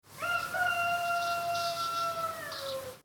00:03 Title: Rooster Publisher
Category: Animal Mood: Alerting Editor's Choice